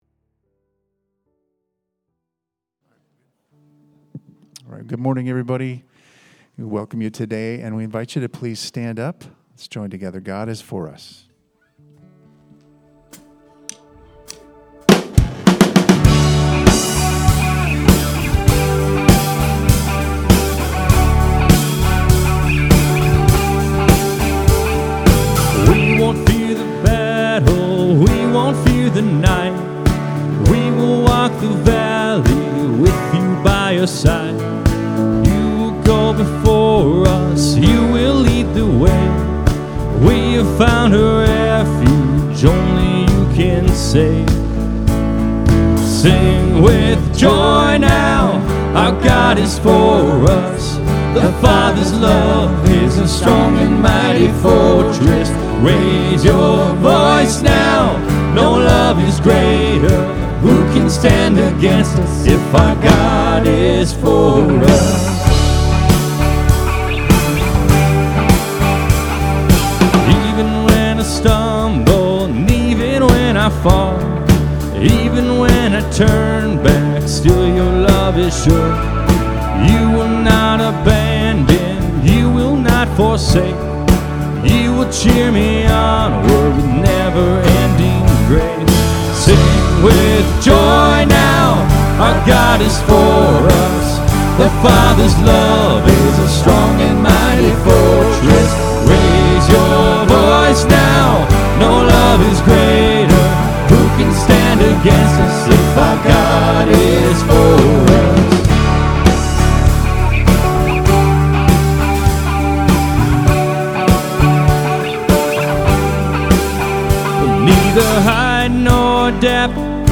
Sunday Sermon: 11-16-25